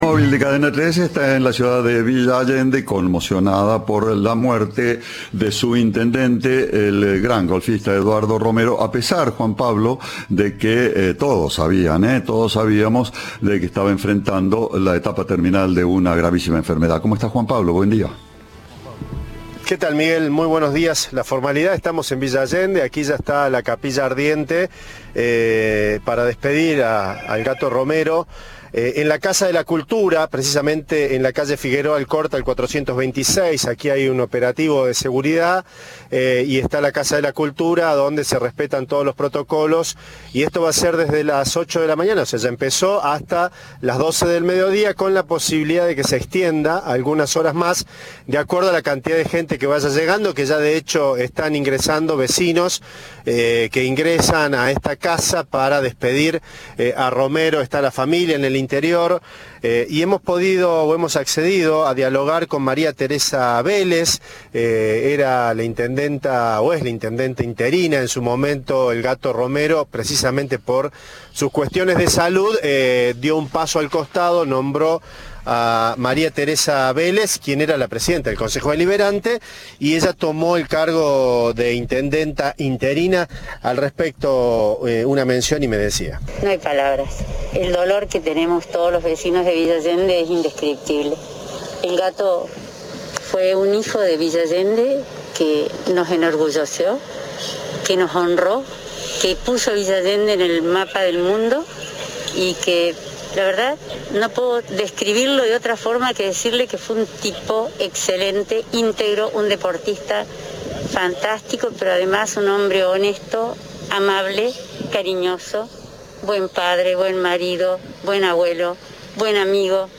El ex golfista e intendente es velado en la Casa de la Cultura de Villa Allende.
Audio. La despedida del Gato Romero en Villa Allende
Informe